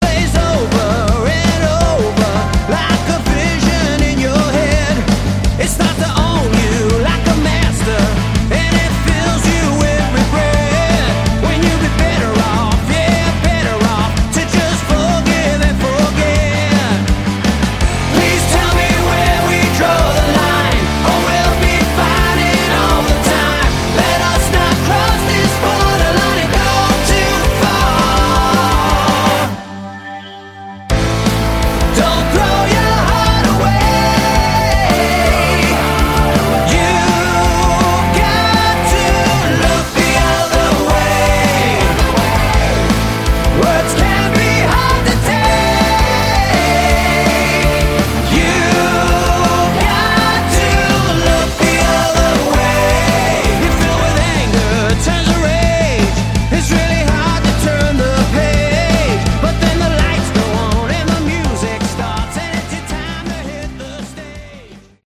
Category: Melodic Rock
keyboards, Grand piano
lead & rhythm guitar
drums
lead vocals
bass
backing vocals